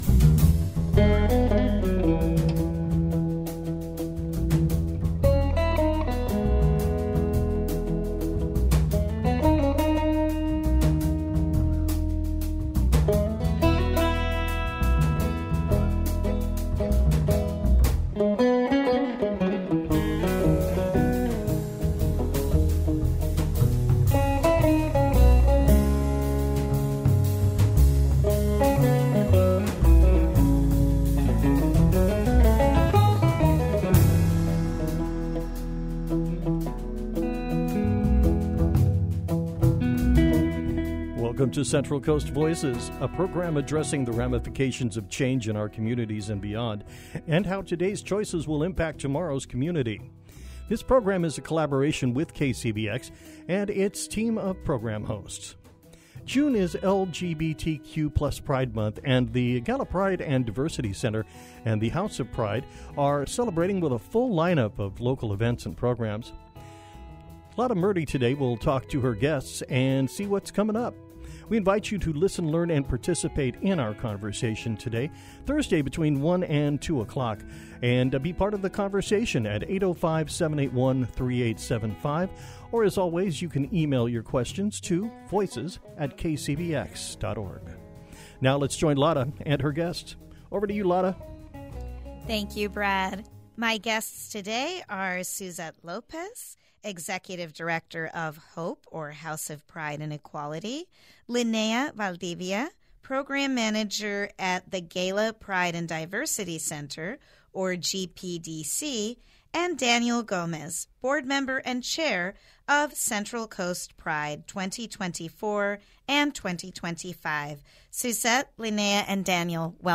An Interview with Musician